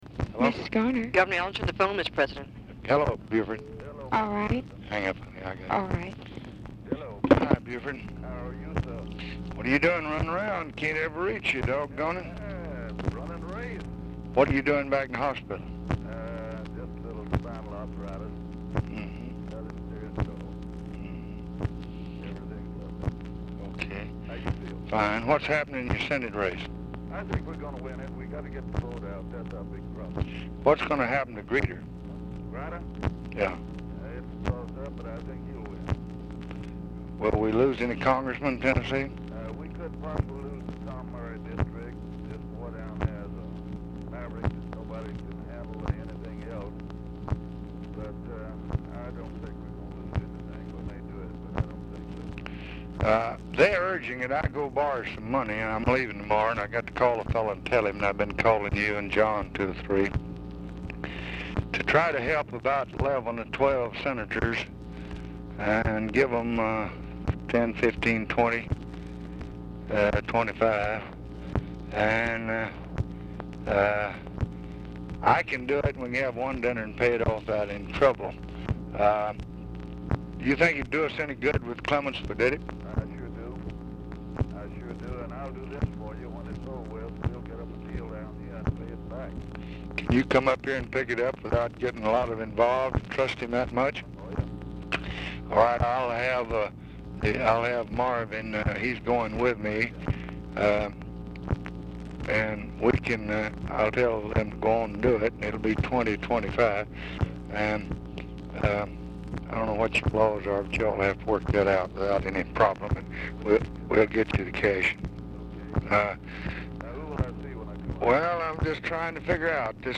Telephone conversation # 10957, sound recording, LBJ and BUFORD ELLINGTON
ELLINGTON IS DIFFICULT TO HEAR
Format Dictation belt